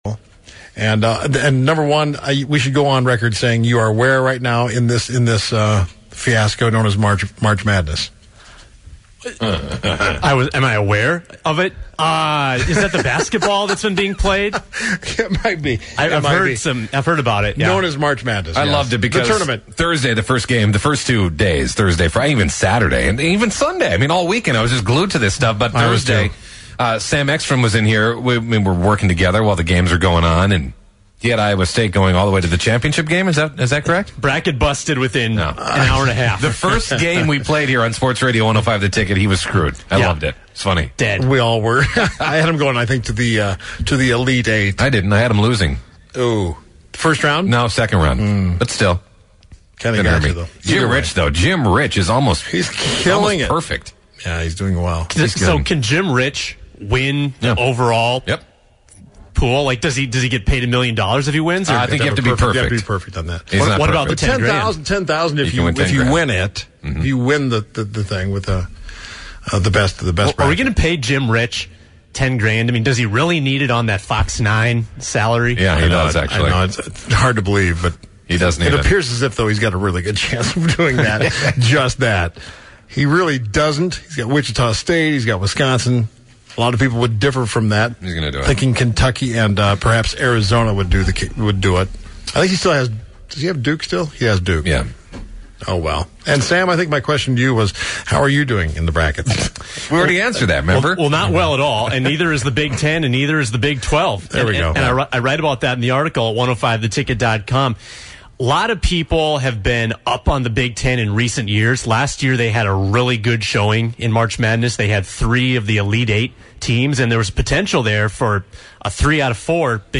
Plus, they play a little NCAA March Madness Trivia with some callers, as well as reveal the Top 5 Best Sports Days of the Year and a few of the silliest sports stories are shared to close out the show.